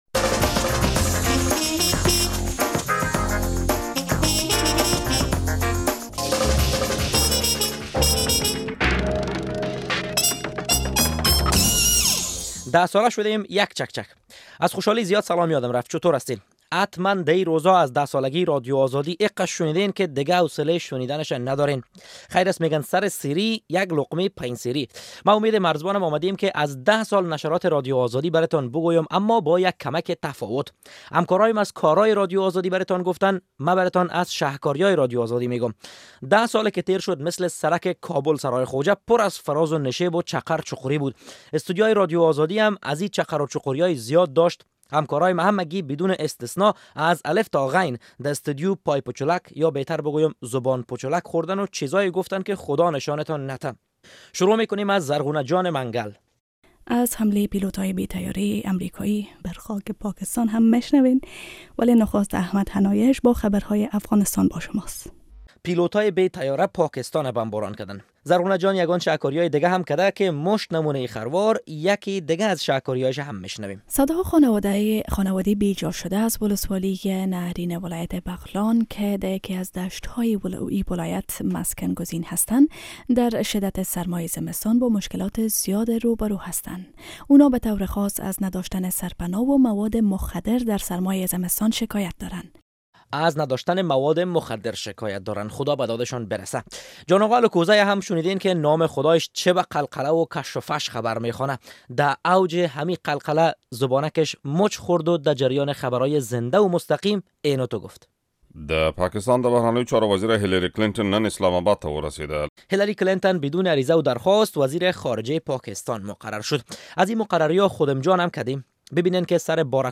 لغزش های لفظی گزارشگران رادیو آزادی در 10 سالی که گذشت
در کنار کار های موثر و تلاش های ثمر بخش، گزارشگران و ژورنالیستان رادیو آزادی از لغزش های لفظی خالی نبوده اند.